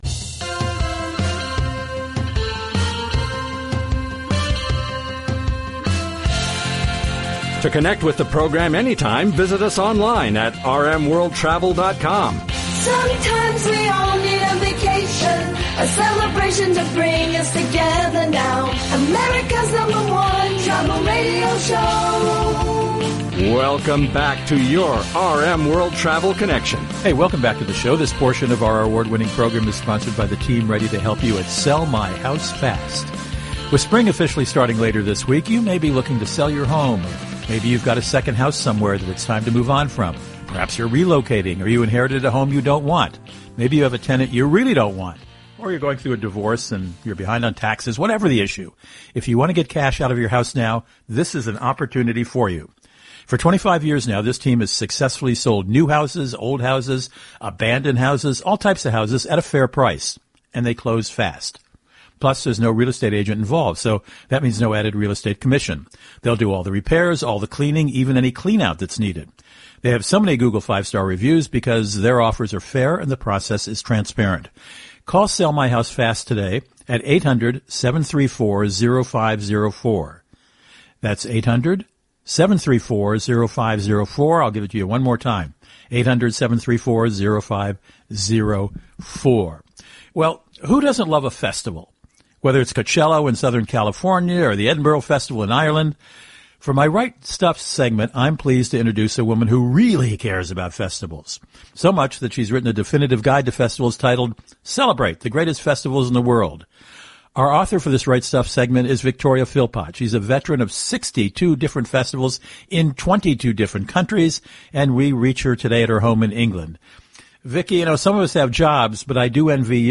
Now that the program has been transmitted over satellite and aired across the USA via our 615+ weekly AM & FM radio stations, you can access the interview again that’s been archived here by clicking the play arrow immediately following this text … https